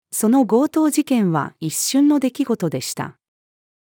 その強盗事件は一瞬の出来事でした。-female.mp3